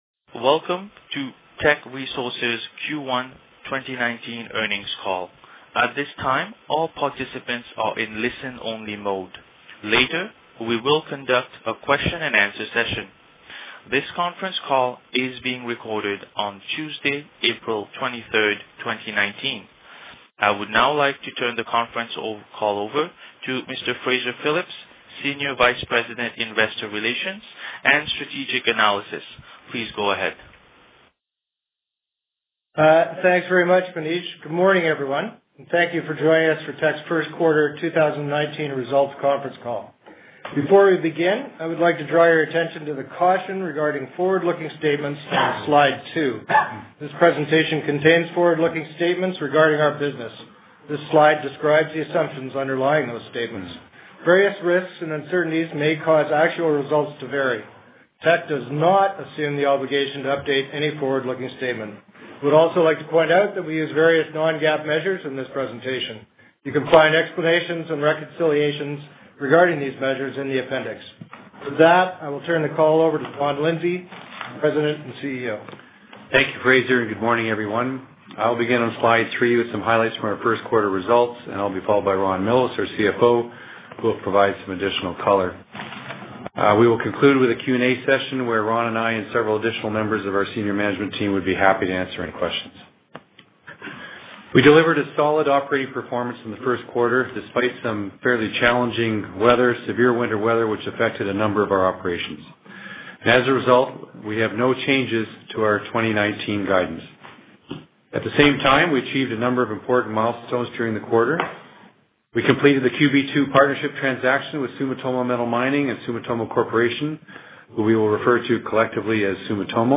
Q1-2019-Conference-Call-Audio.mp3